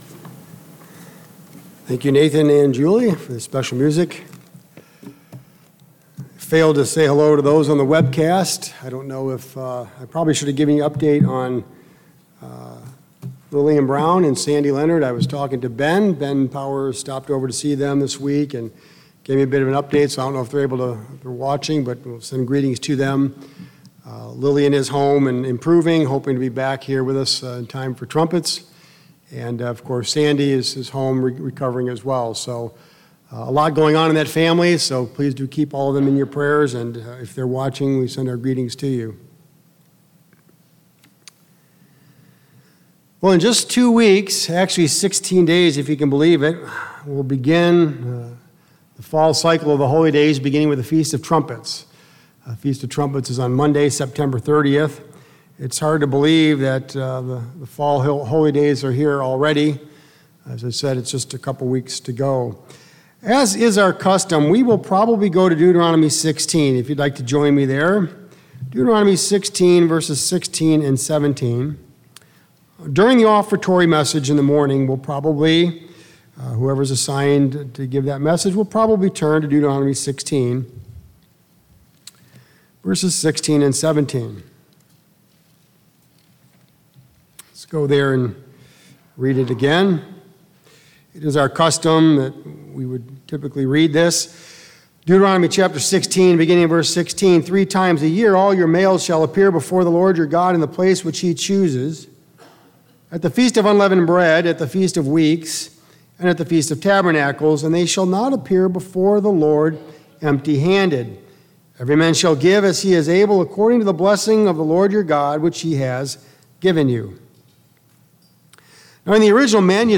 Sermons
Given in Columbus, OH